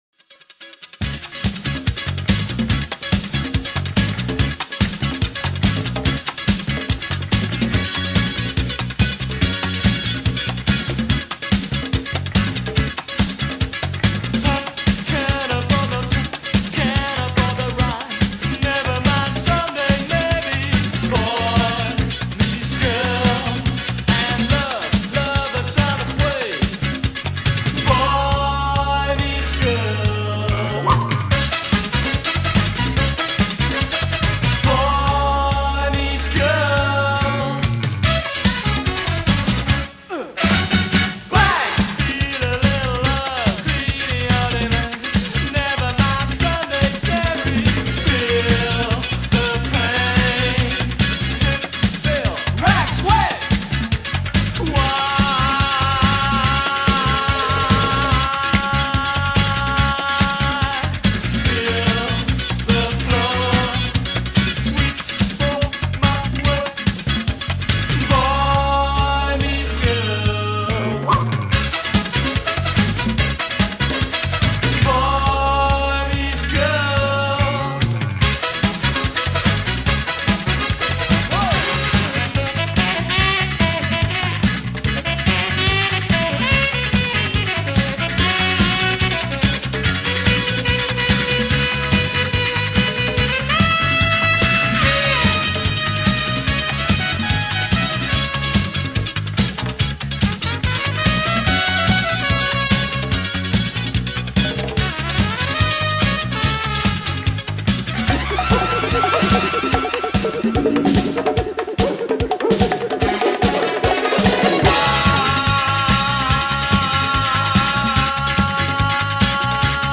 New wave de los 80